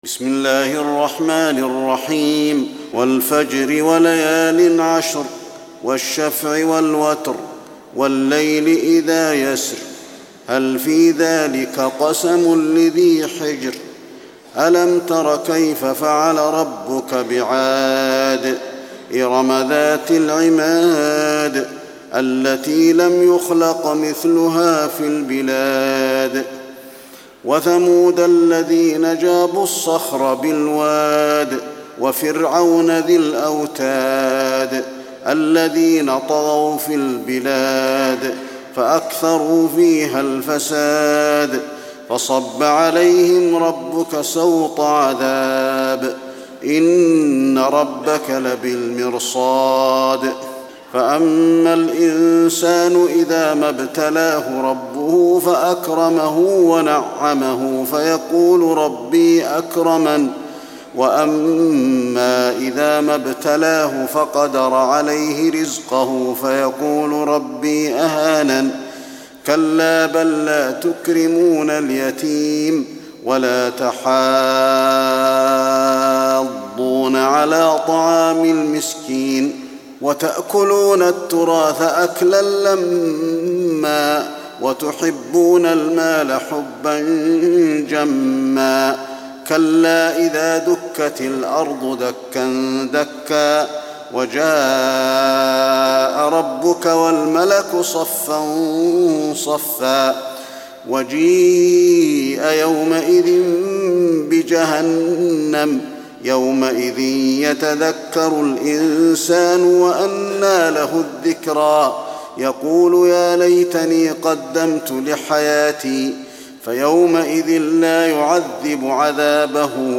تراويح ليلة 29 رمضان 1423هـ من سورة الفجر الى الناس Taraweeh 29 st night Ramadan 1423H from Surah Al-Fajr to An-Naas > تراويح الحرم النبوي عام 1423 🕌 > التراويح - تلاوات الحرمين